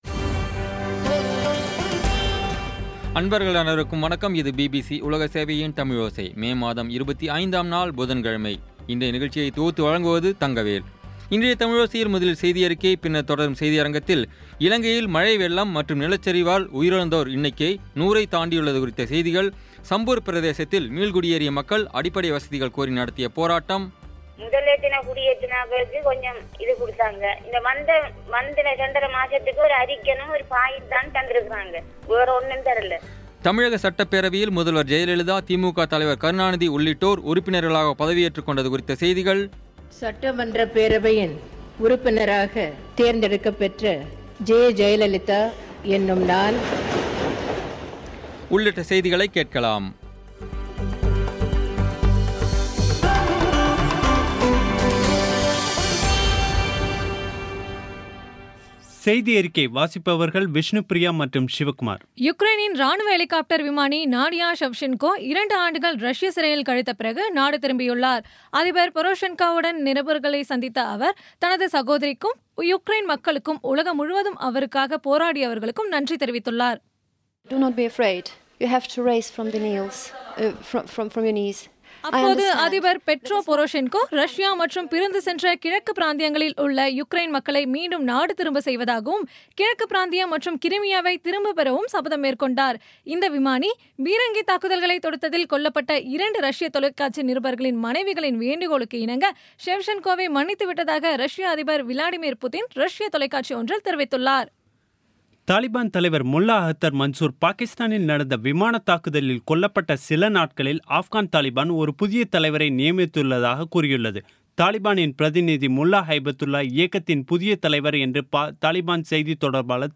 இன்றைய தமிழோசையில், முதலில் செய்தியறிக்கை, பின்னர் தொடரும் செய்தியரங்கில்